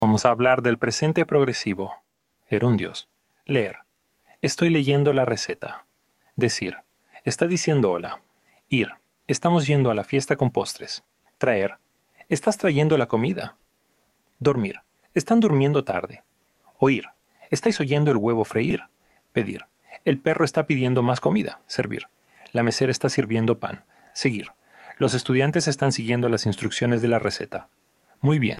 Play the audio to hear how the gerunds are pronounced in a sentence.